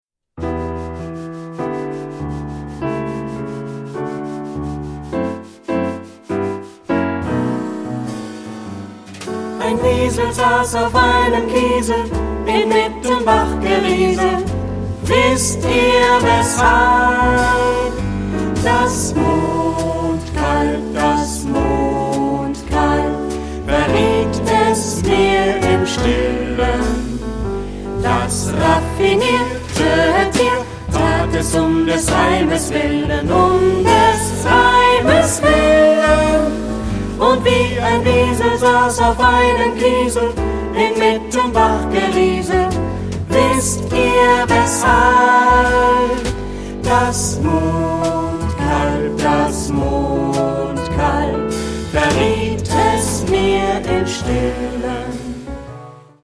Ad libitum (3 voix Ad libitum).
Chanson. Canon. Jazz choral.
jazzy ; rythmé ; léger
Tonalité : mi mineur